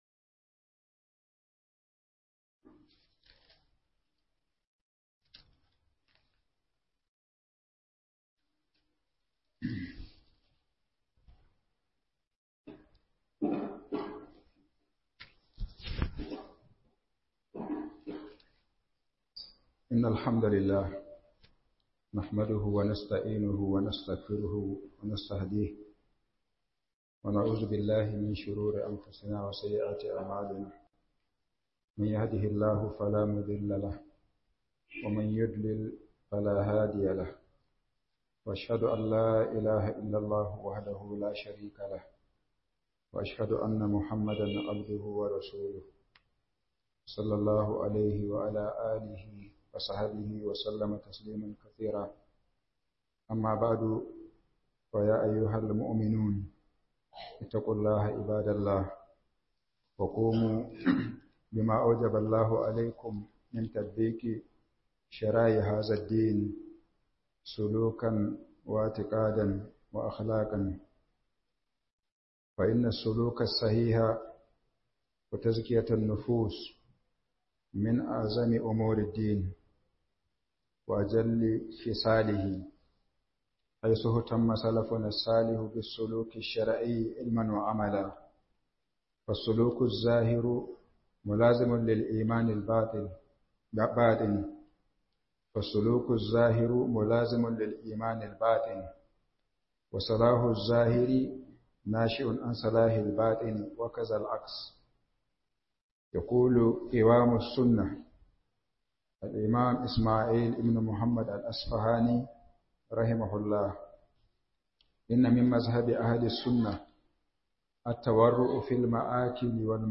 Tunatarwa ga Ahlussumna - Huduba